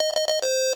autodarts triple sound t16 or t15 Meme Sound Effect
autodarts triple sound t16 or t15.mp3